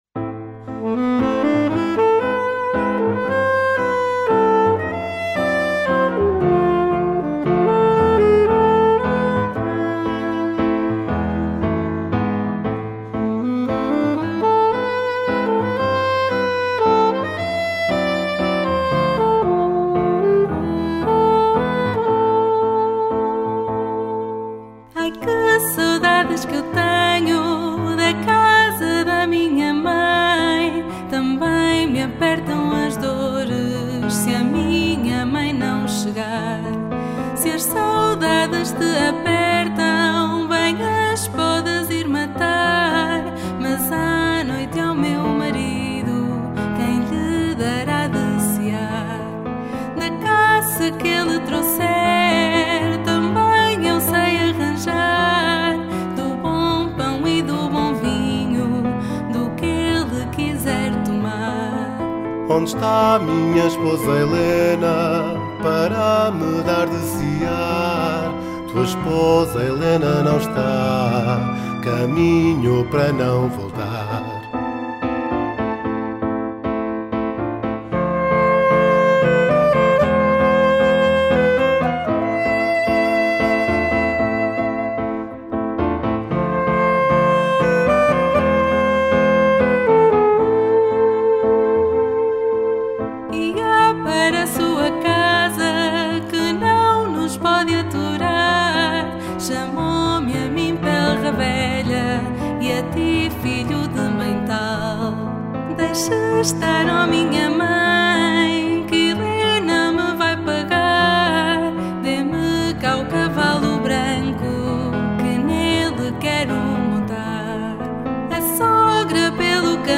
ÁUDIO COM VOZ
Romanceiro Popular de Tradição Oral
Dona Helena - Com Voz Recolha Xarabanda.mp3